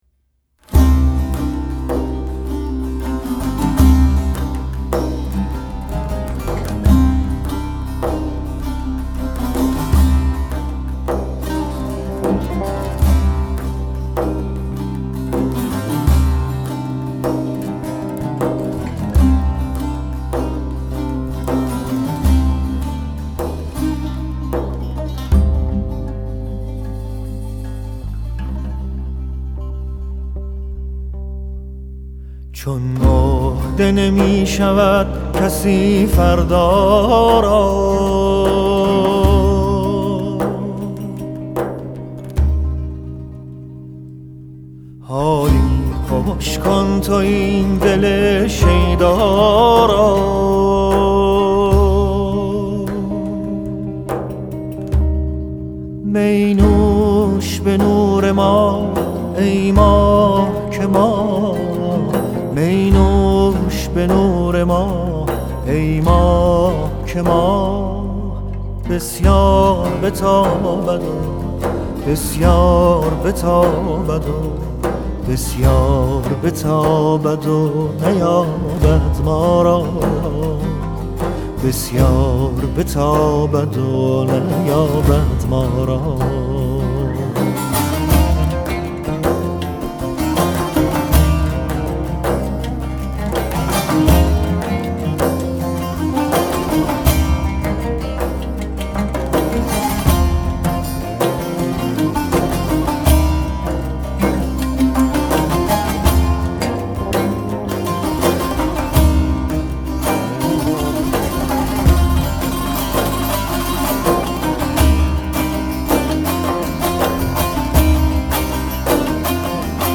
Tasnif